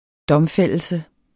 Udtale [ ˈdʌmˌfεlˀəlsə ]